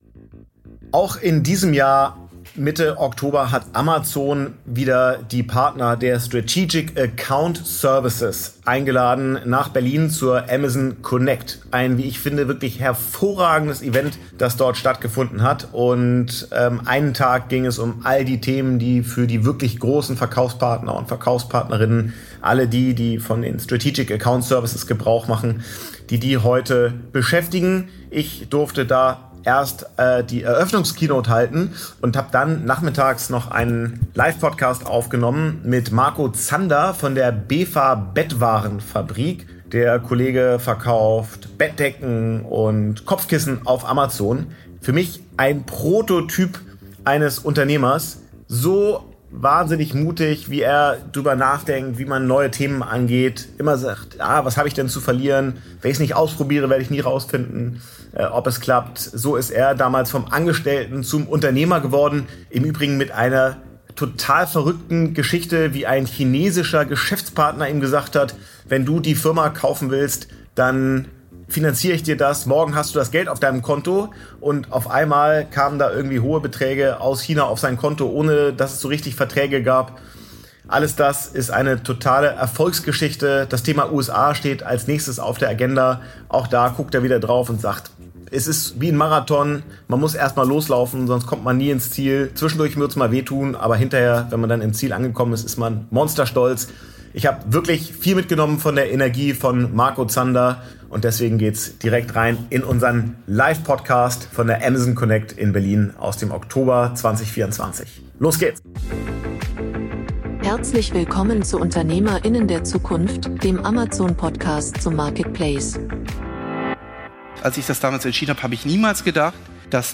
In einem weiteren Live Podcast